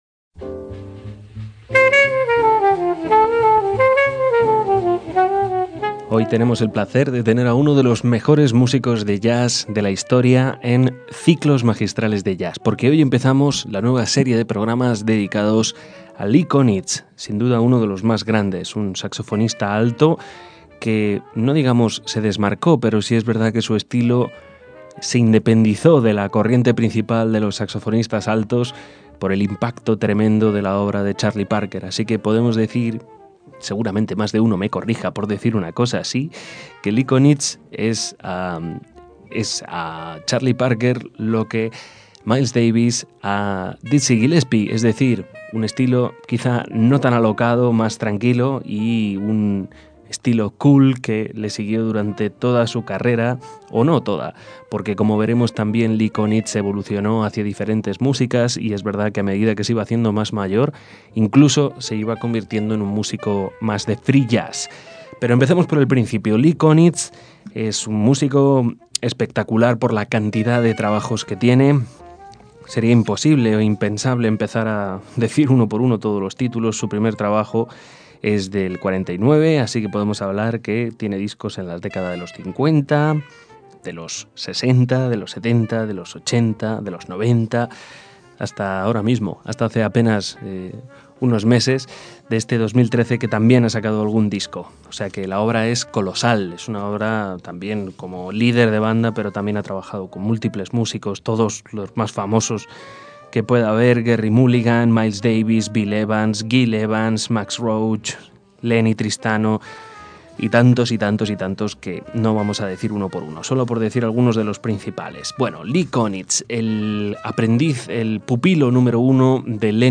saxofonista de jazz